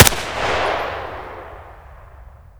m4a1_dist.wav